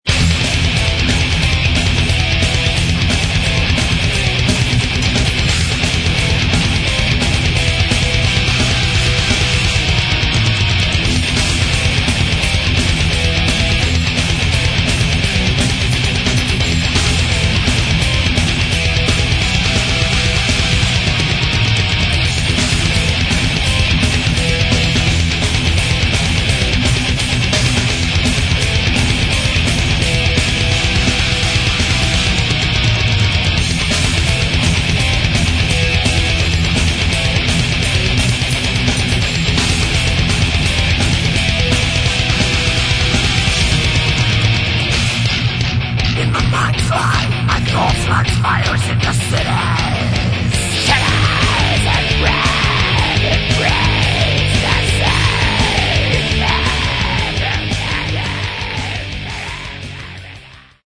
Metal
безумный и уверенно-святотатственный Black Metal.